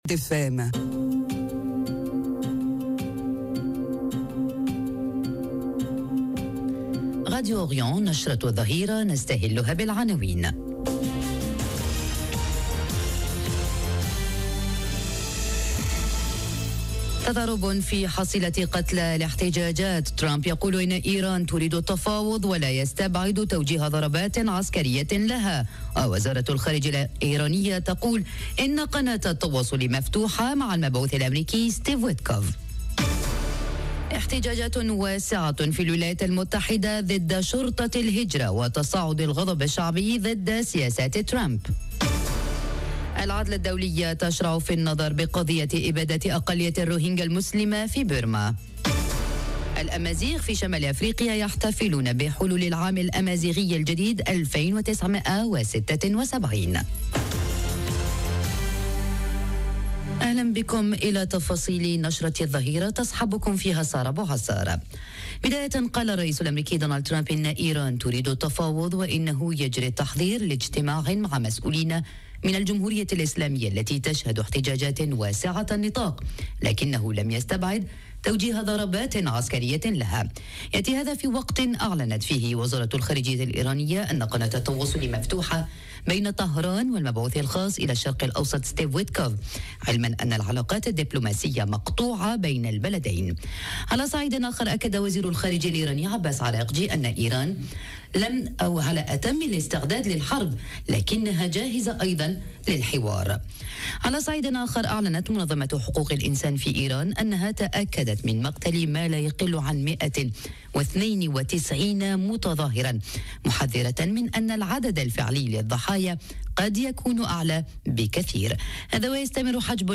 نشرة أخبار الظهيرة: حتجاجات دامية في إيران، ترامب يلوّح بالحوار والضربات، وارتدادات دولية من الشرق الأوسط إلى واشنطن وأوروبا - Radio ORIENT، إذاعة الشرق من باريس